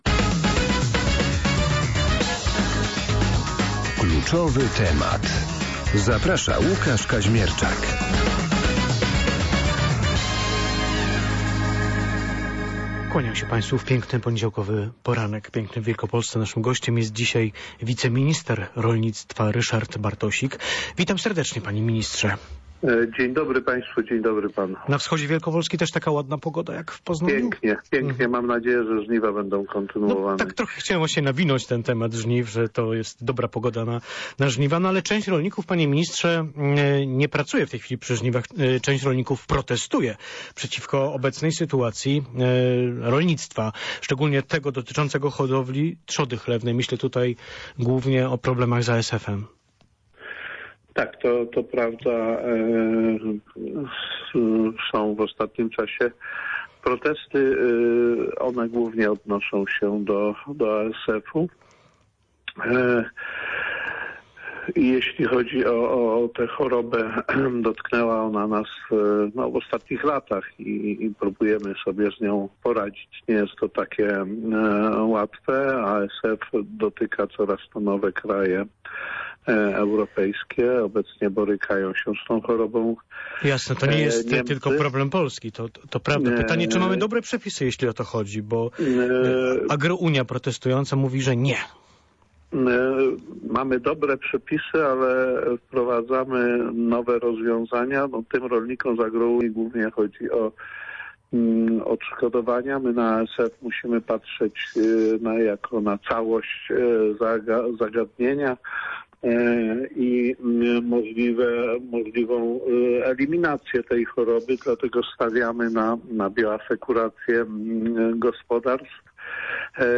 O pomyśle nowelizacji przepisów mówił na antenie Radia Poznań wiceminister rolnictwa Ryszard Bartosik.